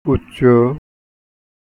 উচ্চ = উচ্+চ